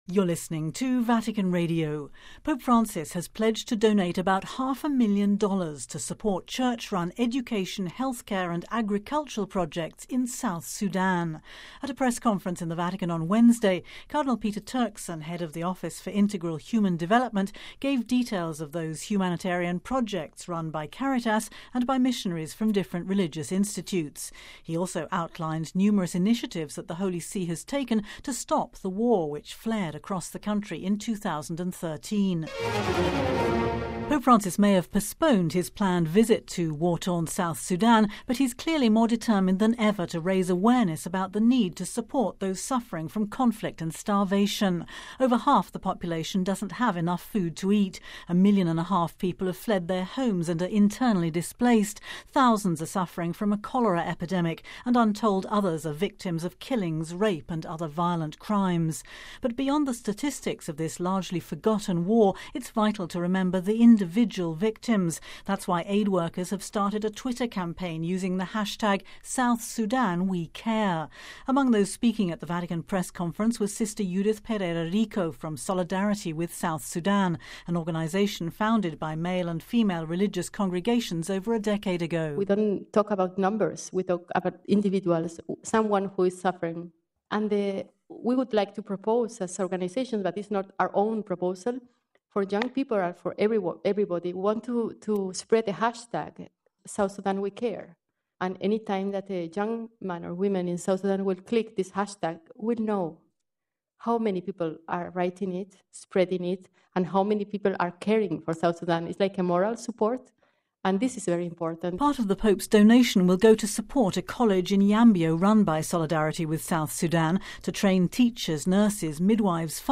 At a press conference in the Vatican on Wednesday, Cardinal Peter Turkson, head of the office for Integral Human Development, led a panel of speakers giving details of those humanitarian projects, run by Caritas and by missionaries from different religious institutes.